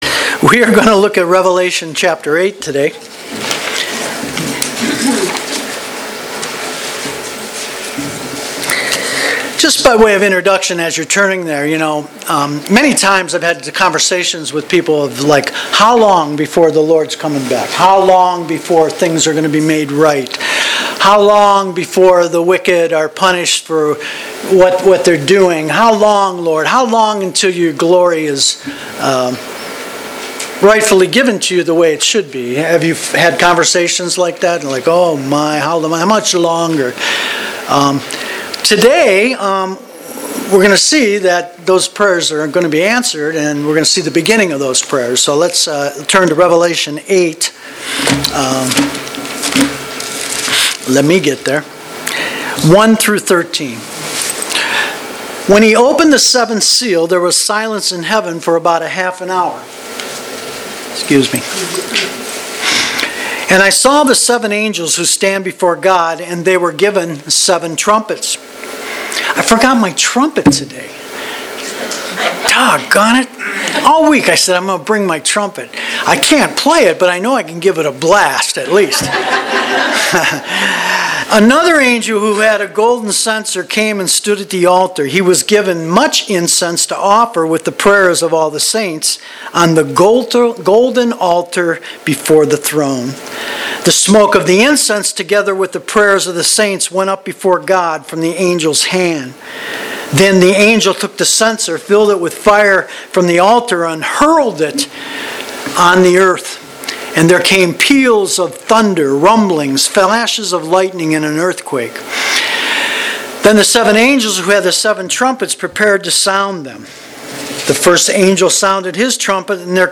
July 22, 2018 (Sunday Morning Service)